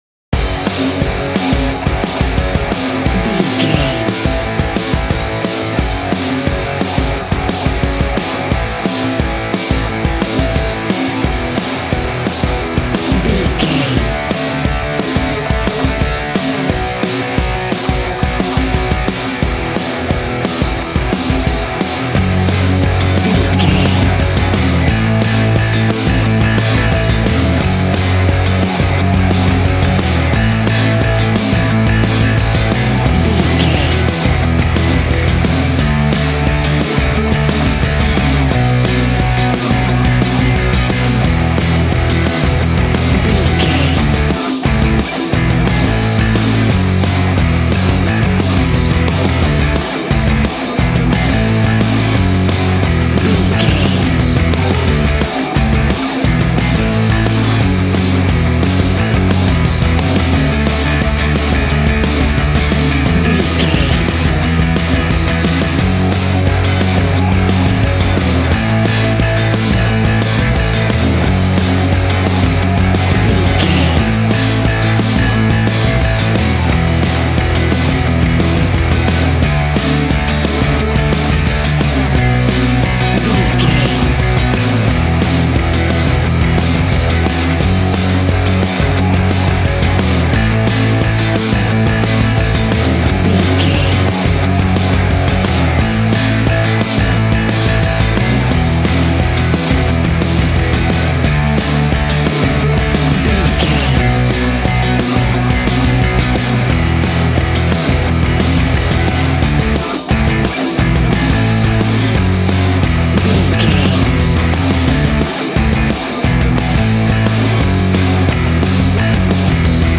Ionian/Major
groovy
powerful
electric organ
drums
electric guitar
bass guitar